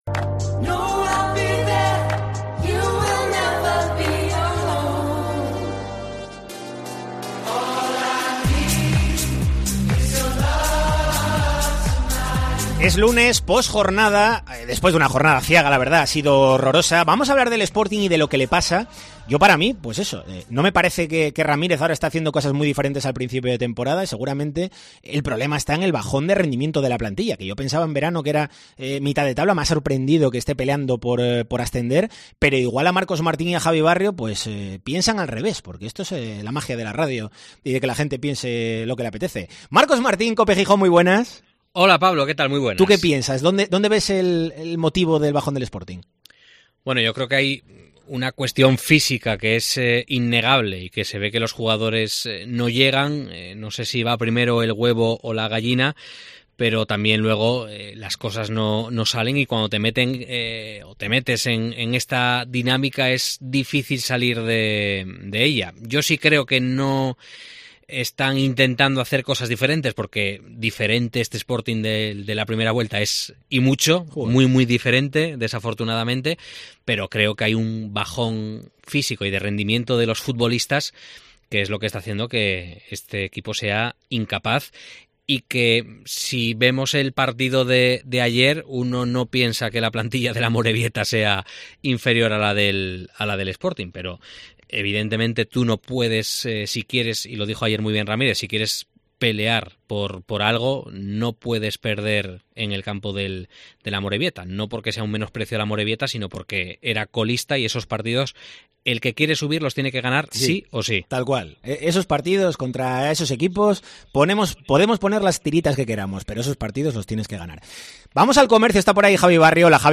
'Tiempo de Opinión rojiblanco' en COPE Asturias En el capítulo de este lunes del 'Tiempo de Opinión rojiblanco' en Deportes COPE Asturias , debatimos acerca de los posibles motivos del bajón del equipo en esta segunda vuelta. ¿Es un problema de entrenador o de plantilla ? Además, valoramos cuál sería el balance de la temporada si el Sporting no logra clasificarse, finalmente, para el playoff de ascenso a final de curso.